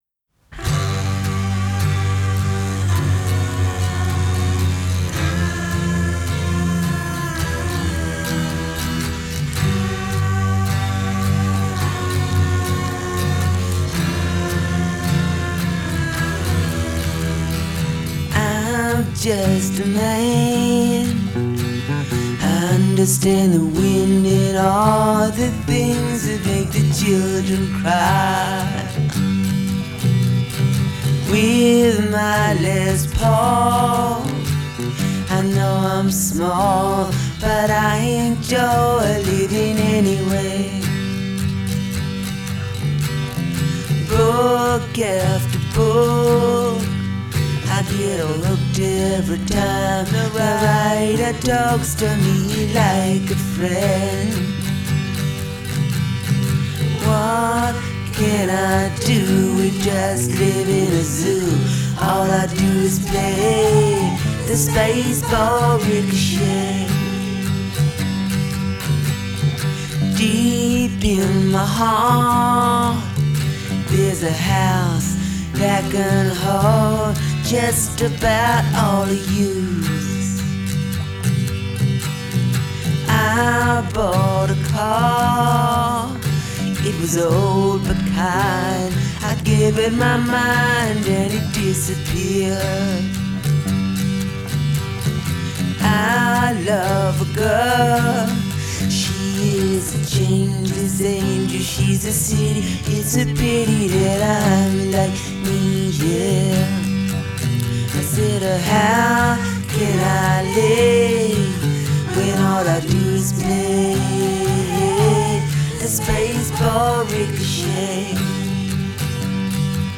Жанр: Hard Rock, Pop-Rock, Glam Metal